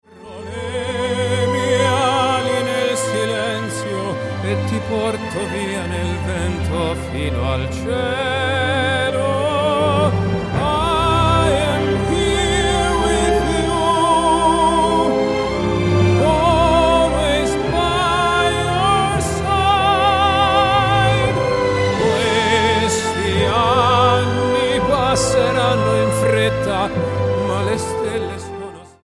STYLE: Classical